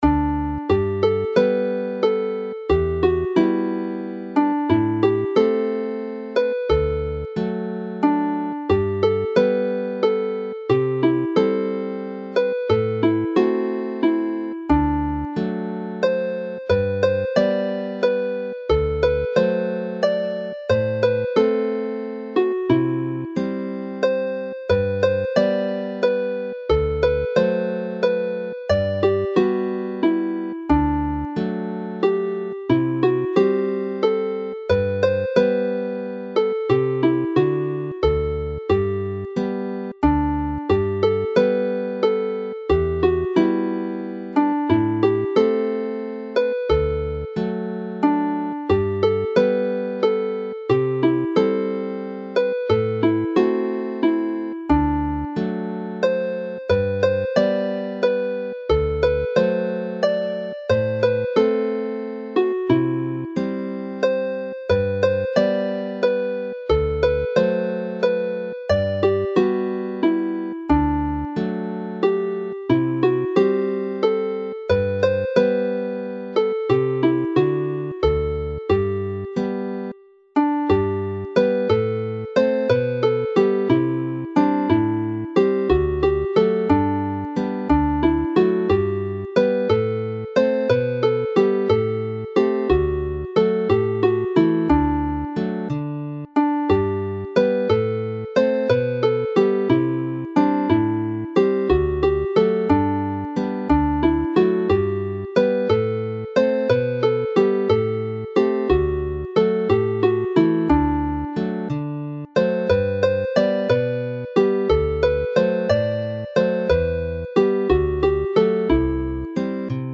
Play the set slowly